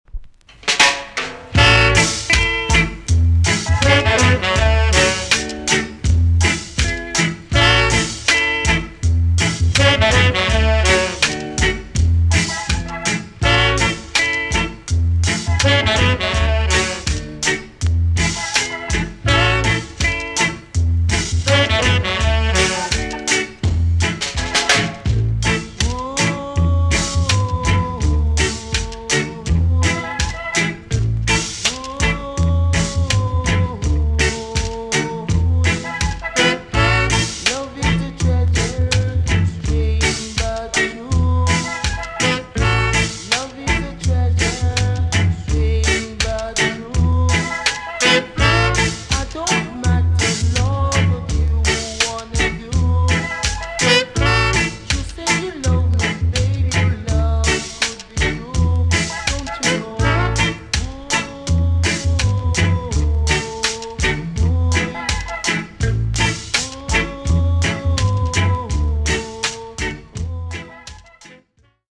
A.SIDE EX- 音はキレイです。